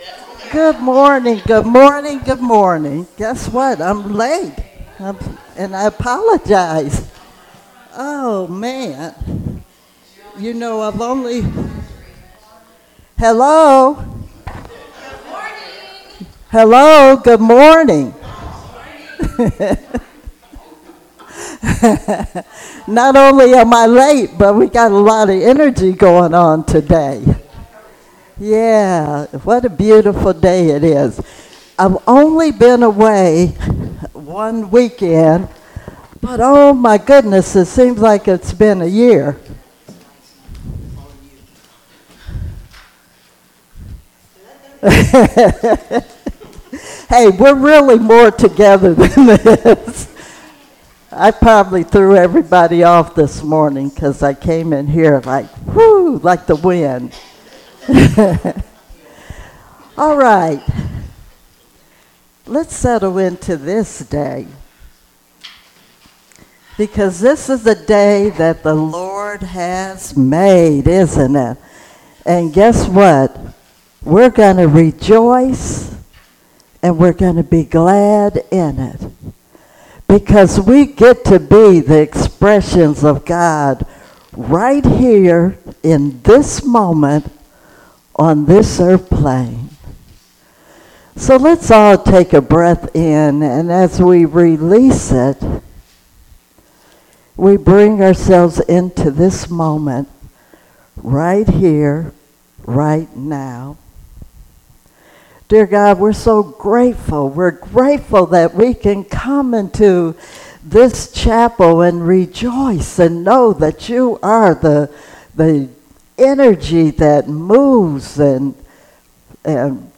Series: Sermons 2023